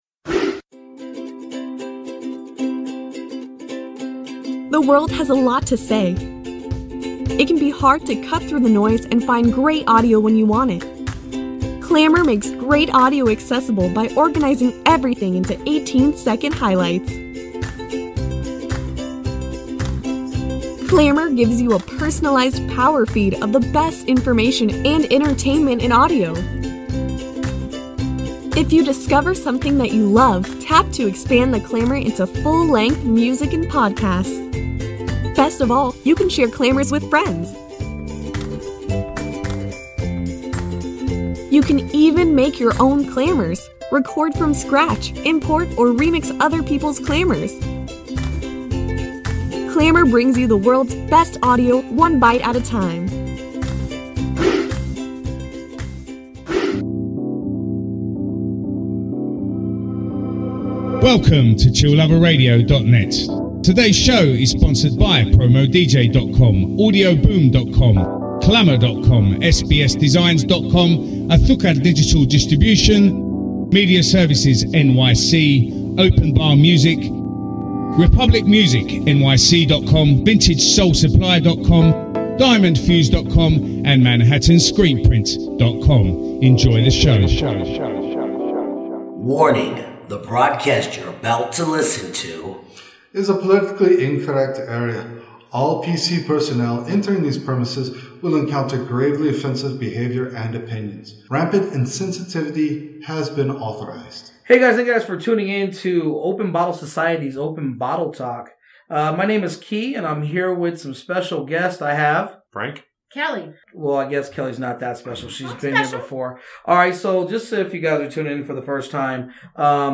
Styles: Beer Talk, Beer News, Beer, Talk Show